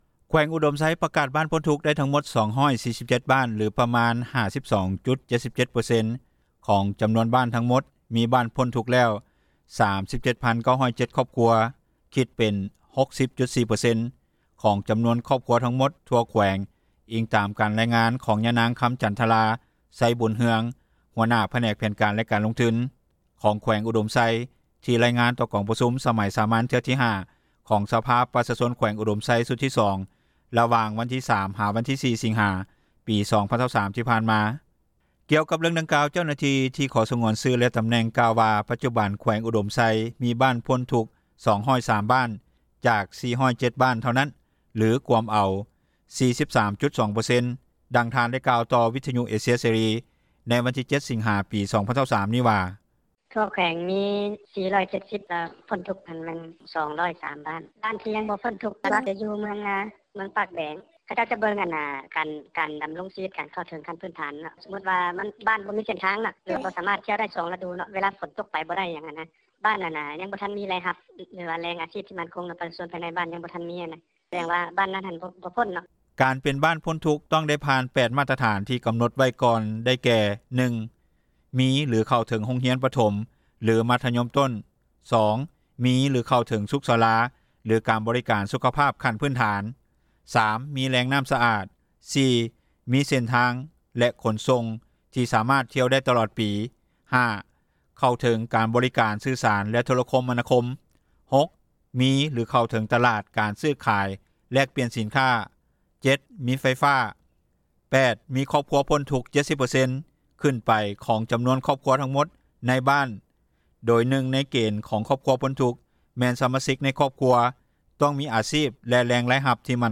ດັ່ງເຈົ້າໜ້າທີ່ ເມືອງງາອແຂວງອຸດົມໄຊກ່າວວ່າ:
ດັ່ງຊາວບ້ານ ຢູ່ເມືອງງາ ແຂວງອຸດົມໄຊ ທ່ານໜຶ່ງກ່າວວ່າ: